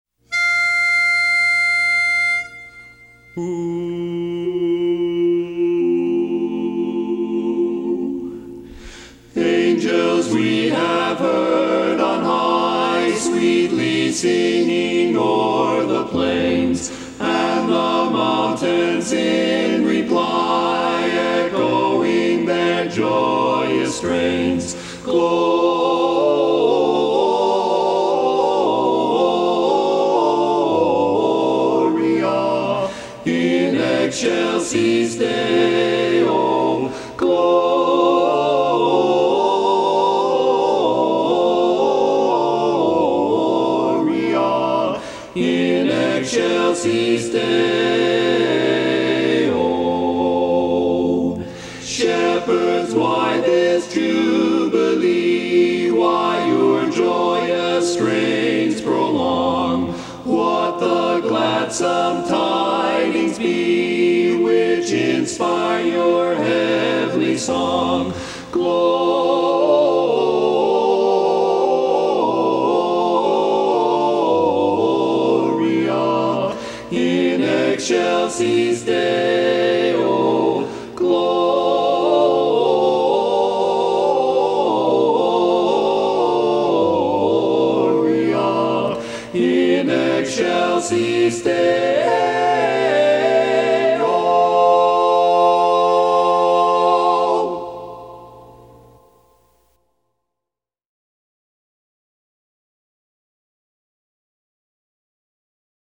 Barbershop
Tenor